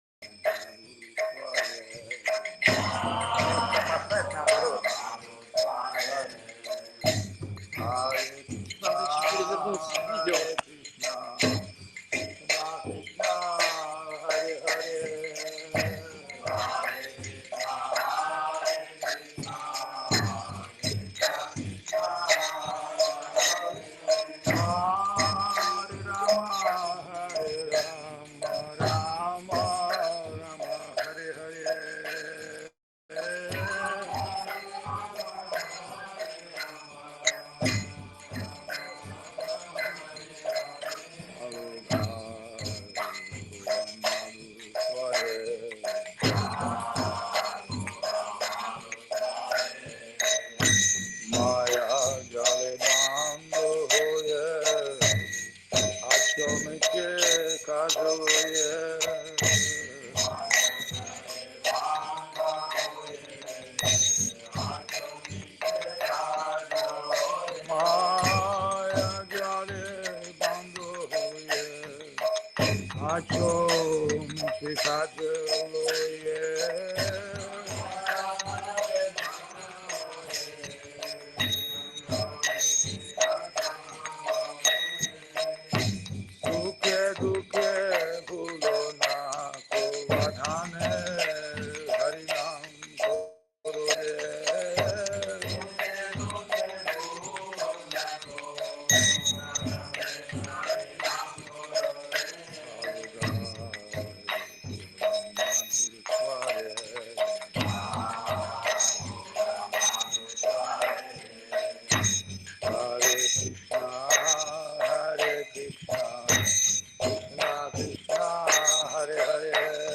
Чиангмай, Таиланд
Лекции полностью